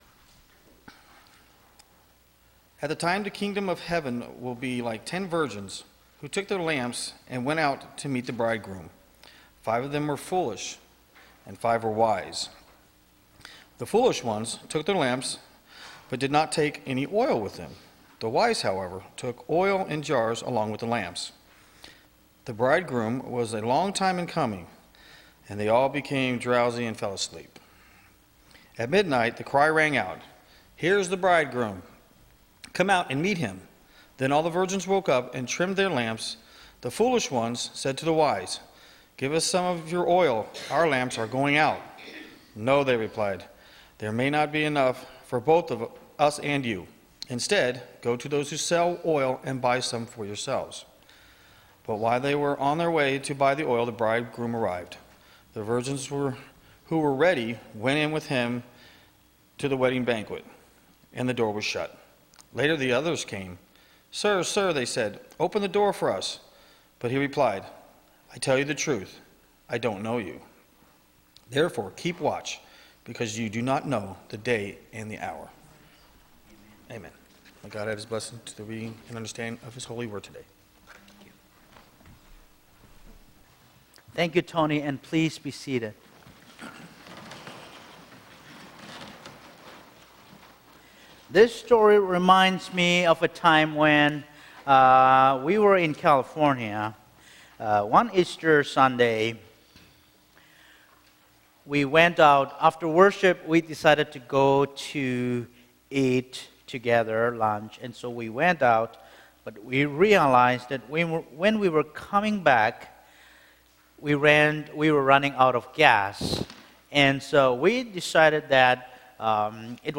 Audio Recording of July 5th Worship Service – Now Available
The audio recording of our latest Worship Service is now available.